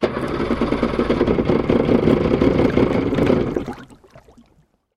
Звук не завелся гидроцикл